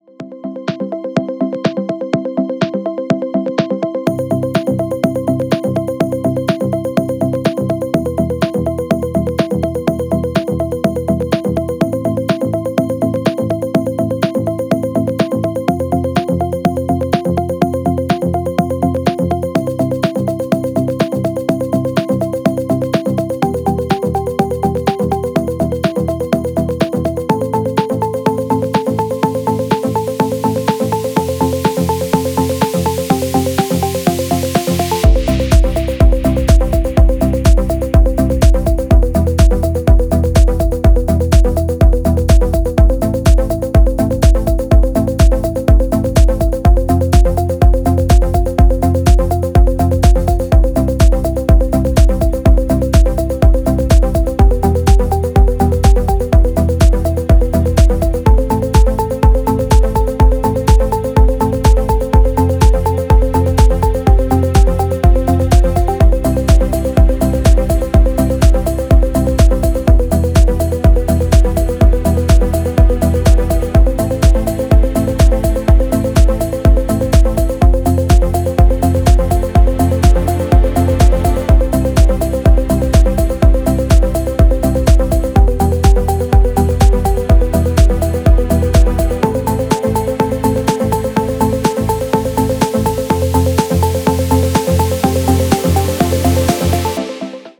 Electronic
без слов
нарастающие
deep progressive
Tech House
progressive house
minimal